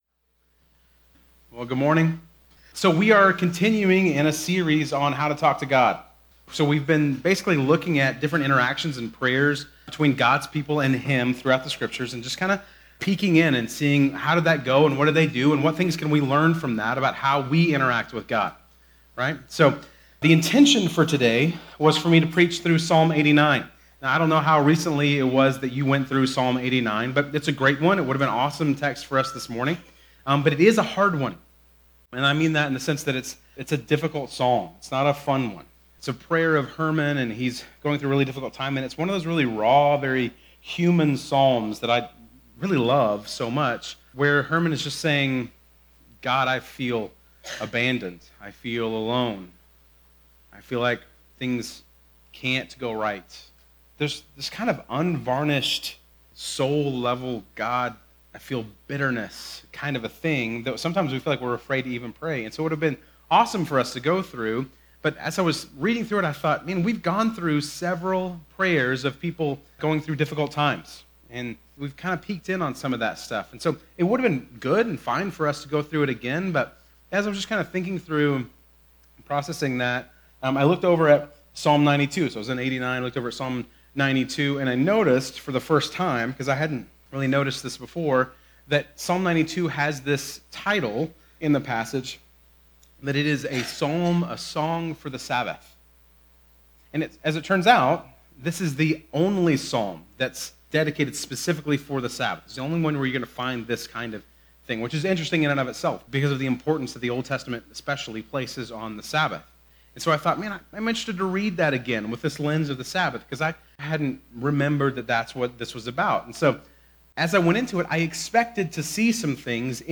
Bible Text: Psalm 92:1-15 | Preacher